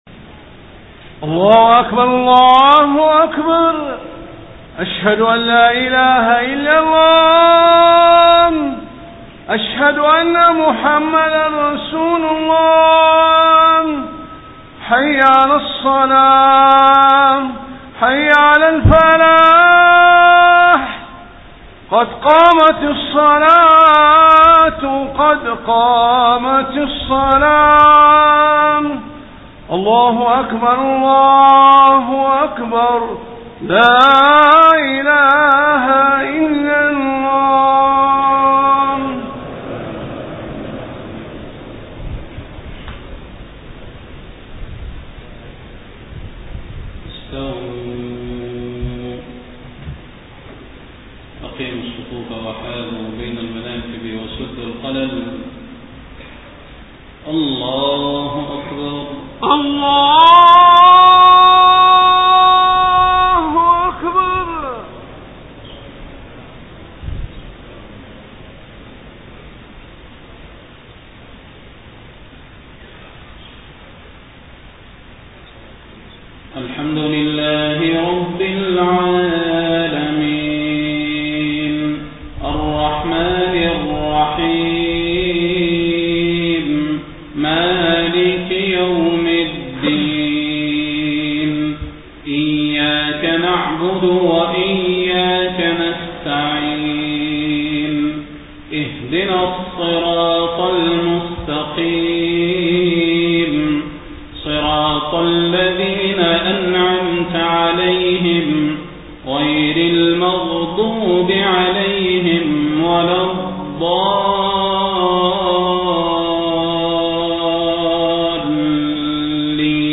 صلاة العشاء 1 ربيع الأول 1431هـ من سورة يونس 57-65 > 1431 🕌 > الفروض - تلاوات الحرمين